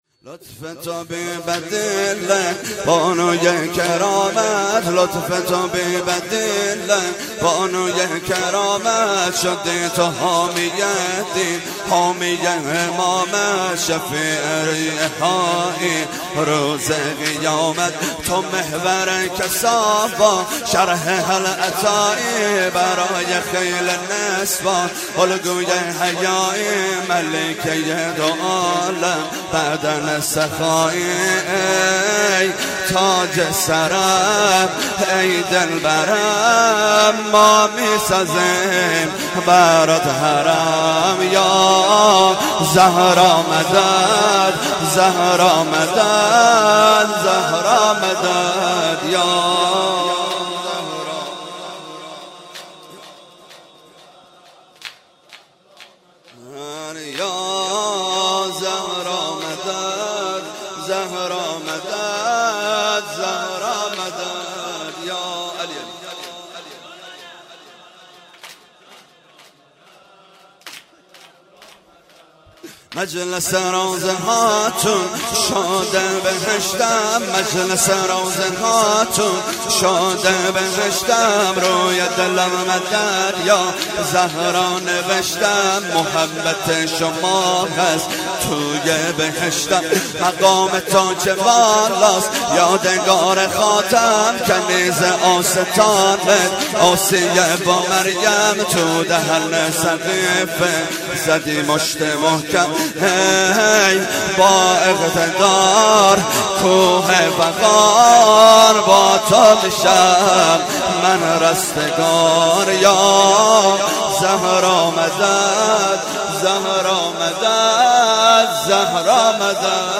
شب اول ویژه برنامه فاطمیه دوم ۱۴۳۹